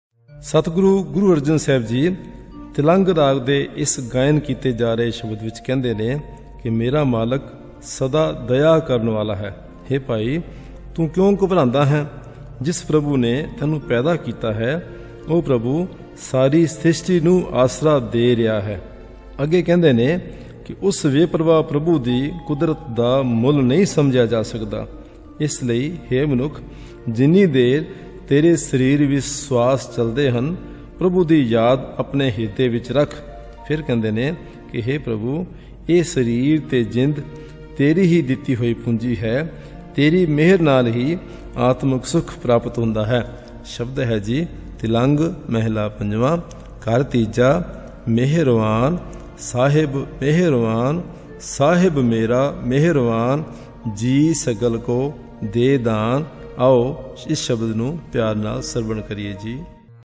Sung in Raag Tilang; Taals Teen taal(16), Dadra(6).
This entry was posted in Shabad Kirtan and tagged , , , , , , .